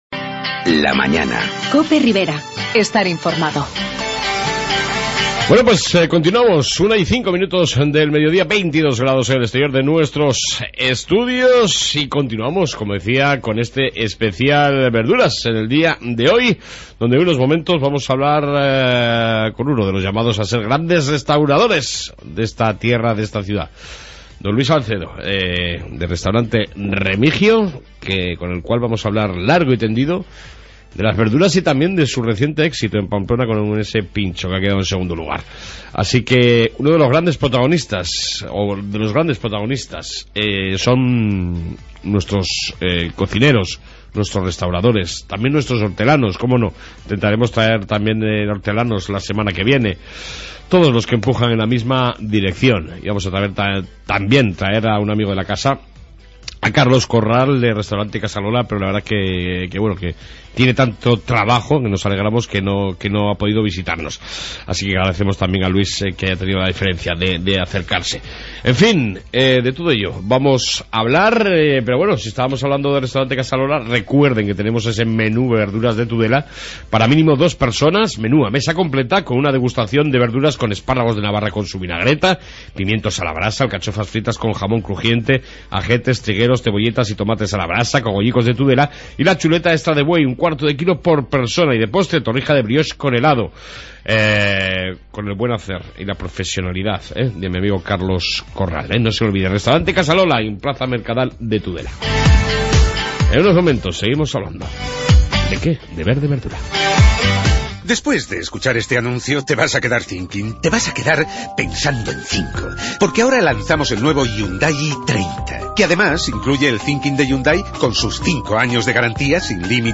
"Especial verduras" entrevista